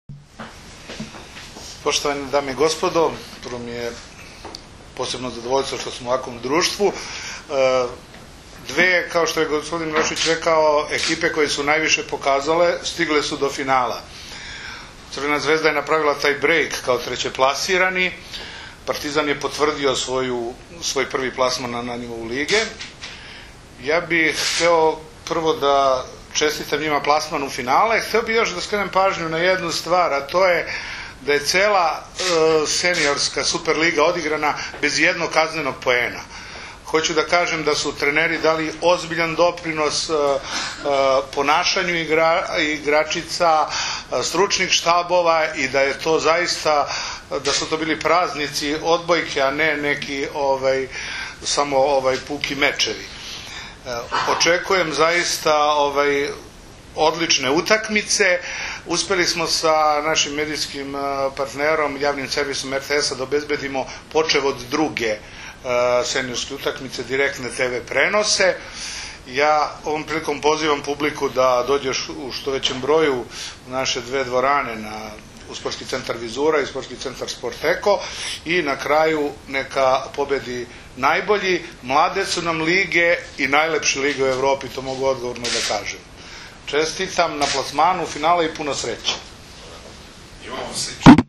Tim povodom, danas je u prostorijama Odbojkaškog saveza Srbije održana konferencija za novinare